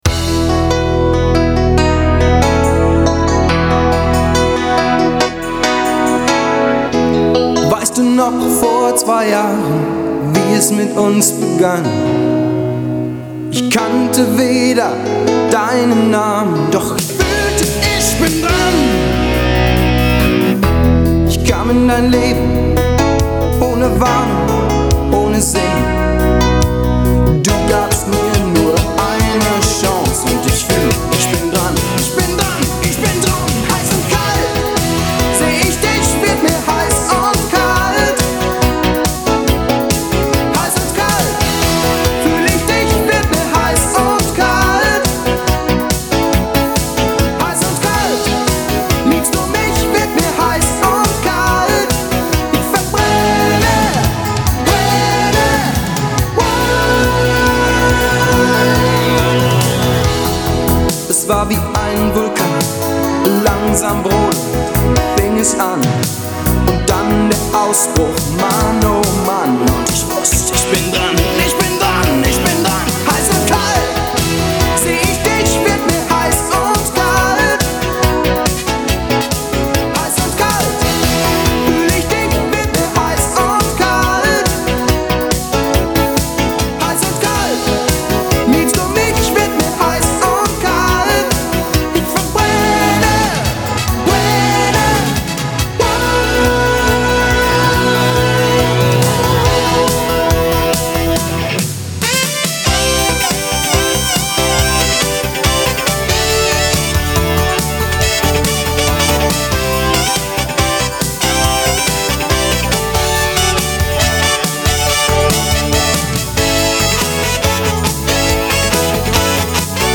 Sänger